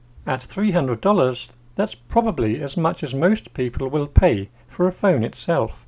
DICTATION 7